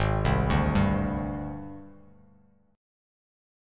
AbM7b5 chord